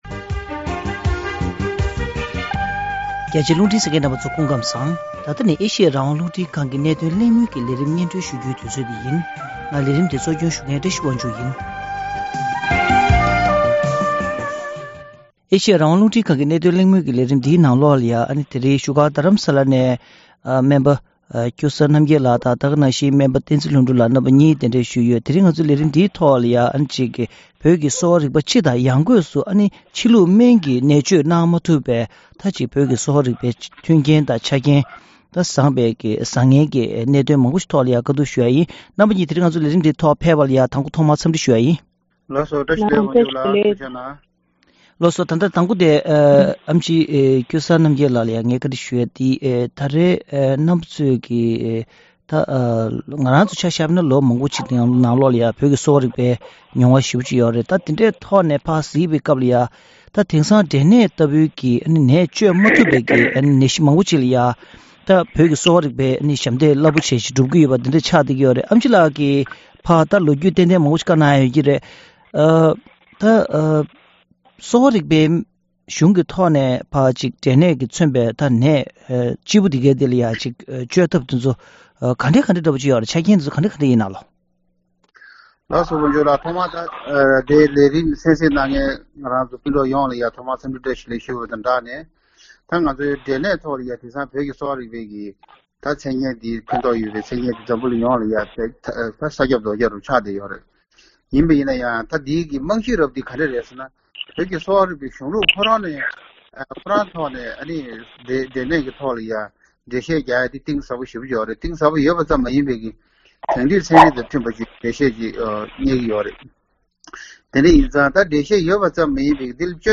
གླེང་མོལ་ཞུས་པའི་ལས་རིམ་ཞིག་ལ་གསན་རོགས་གནང་།